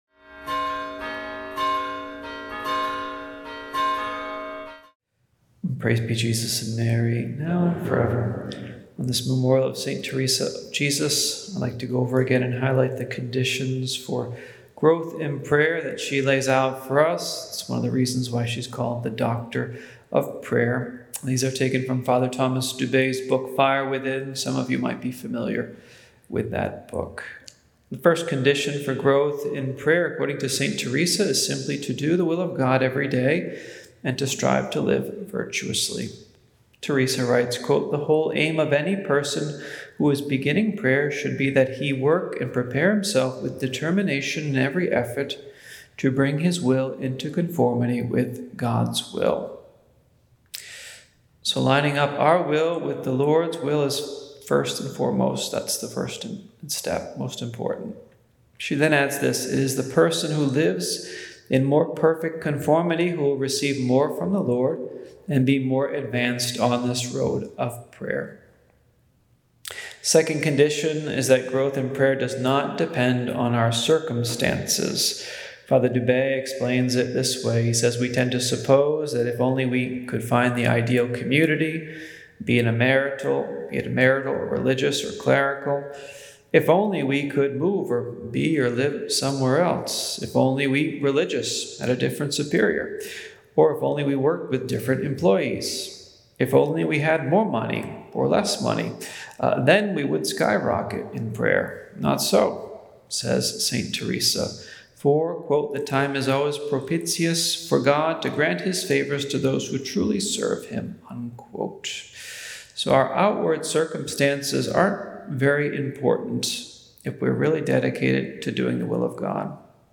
St. Teresa’s 9 Virtues to Advance Spiritually- Oct 15 – Homily